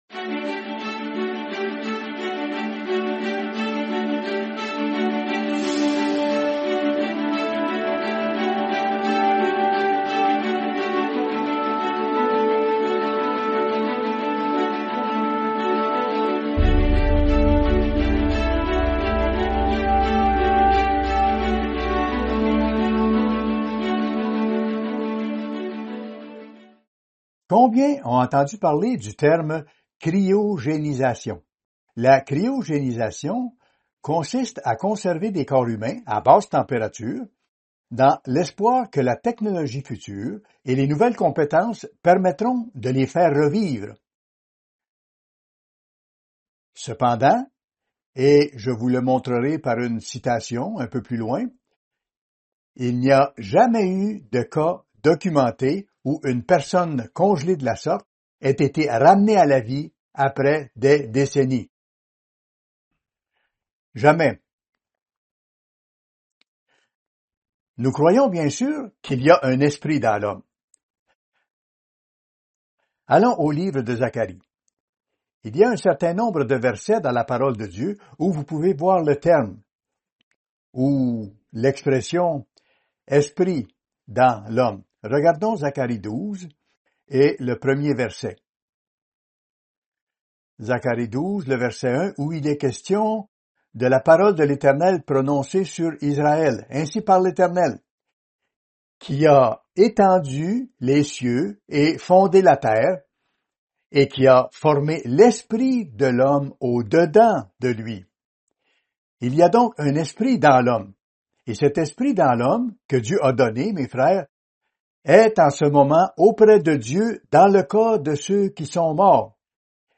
Fête des Tabernacles – 3e jour La valeur de la première résurrection